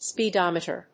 音節speed・om・e・ter 発音記号・読み方
/spɪdάməṭɚ(米国英語), spɪdˈɔmətə(英国英語)/